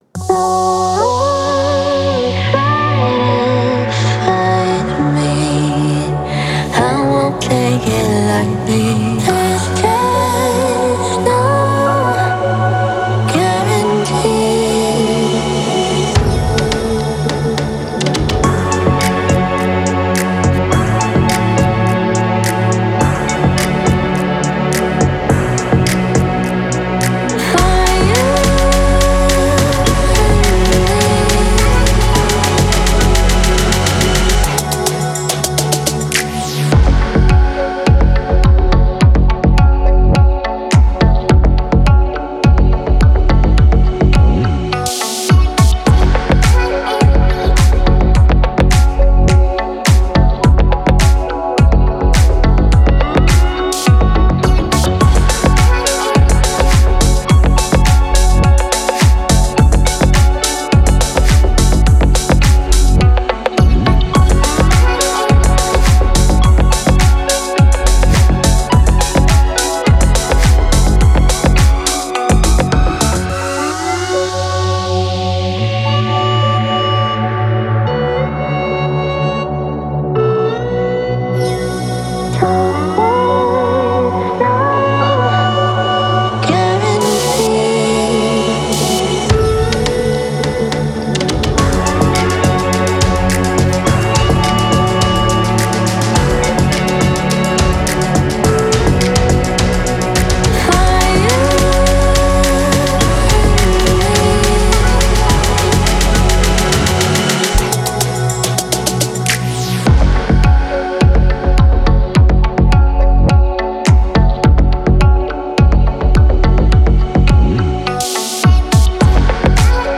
это мощная композиция в жанре альтернативного рока